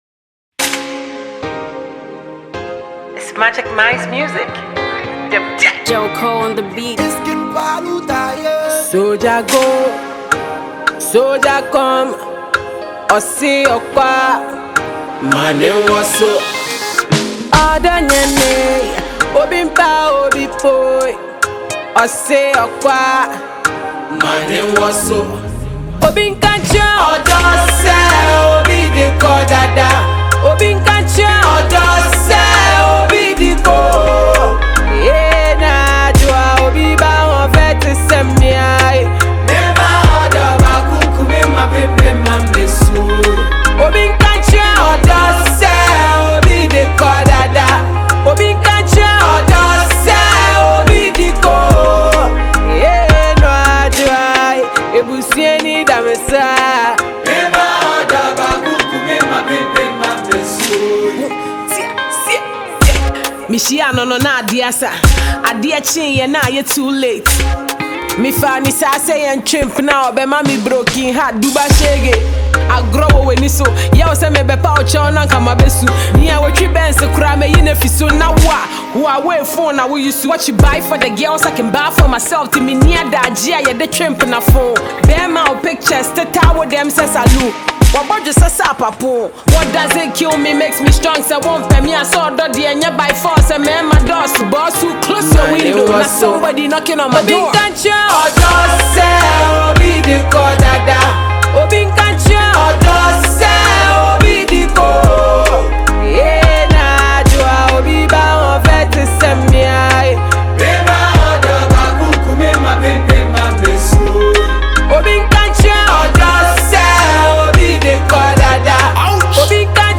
a Ghanaian hardcore female rapper
Ghana Music